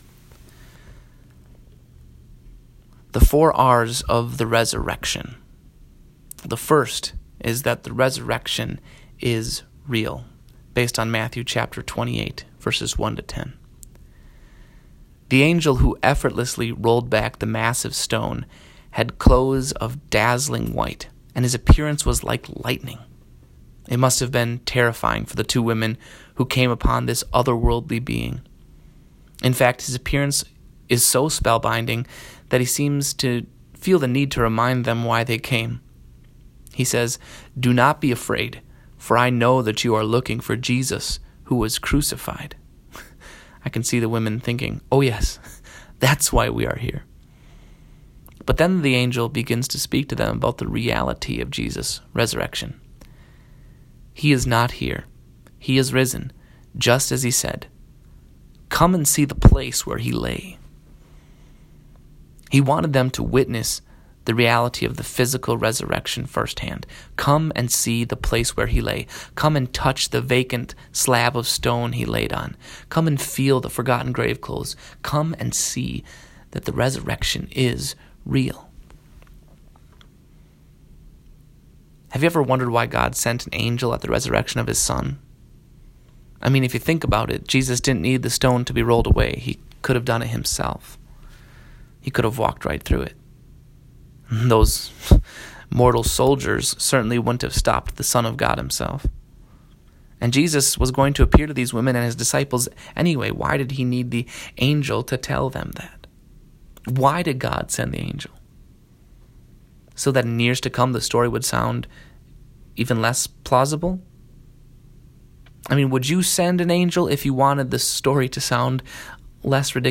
Four sermonettes based on a compilation of the Easter gospels.